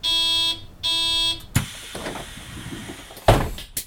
AbfahrtHaltestelle.mp3